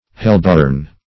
hellborn - definition of hellborn - synonyms, pronunciation, spelling from Free Dictionary
Hellborn \Hell"born`\, a.